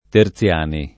[ ter ZL# ni ]